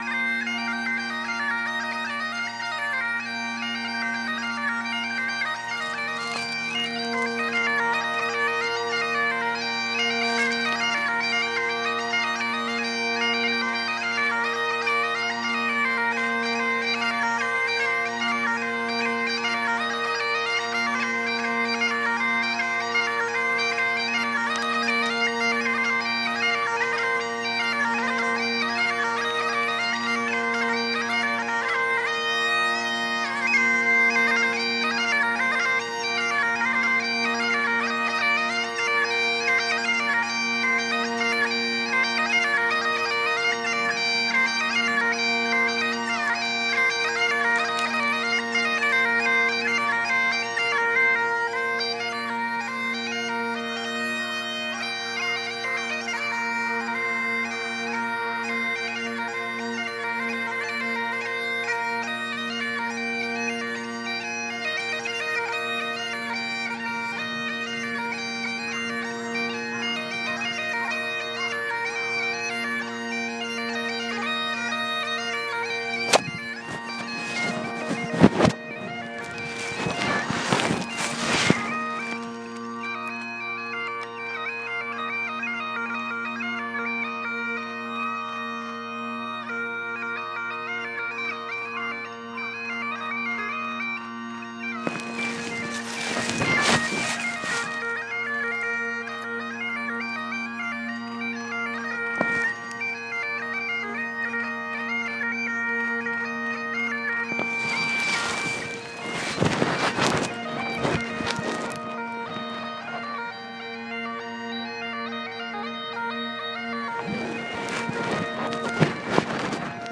Bagpipes in Koganei park